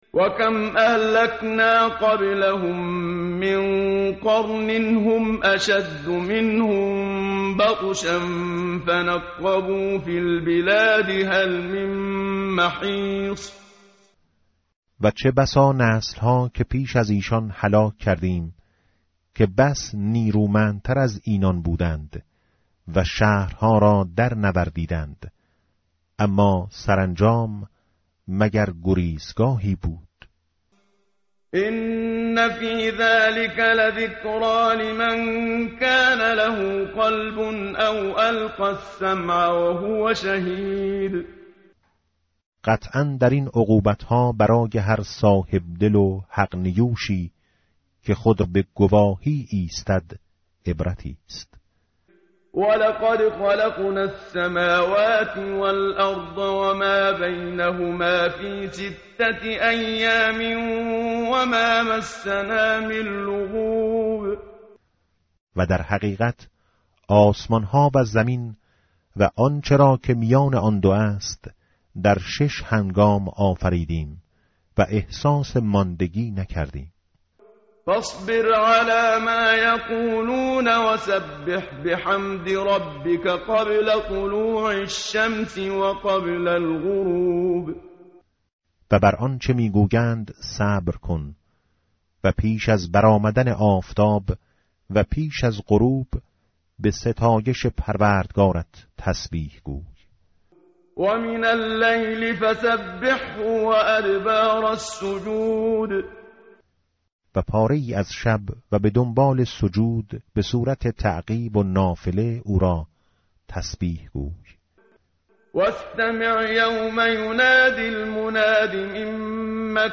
tartil_menshavi va tarjome_Page_520.mp3